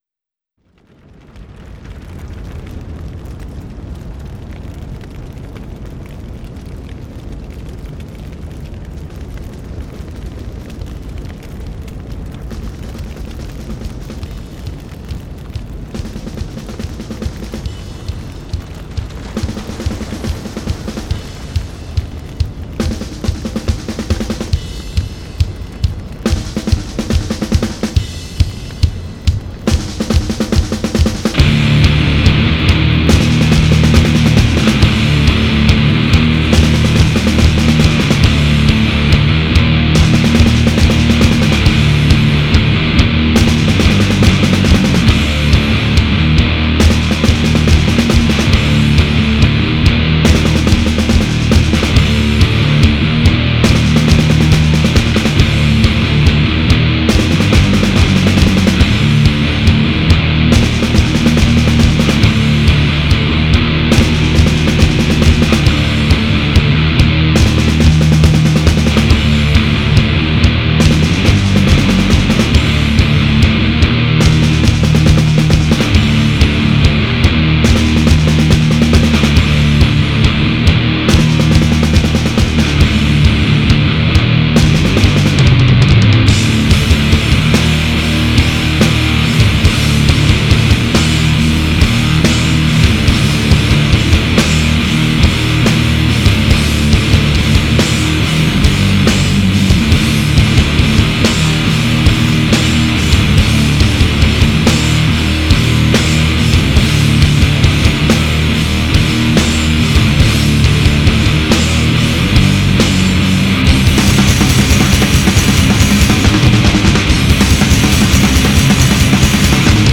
Un peu de sludge doom basque ça vous branche ?
Voix d'outre tombe
déflagrations tonitruantes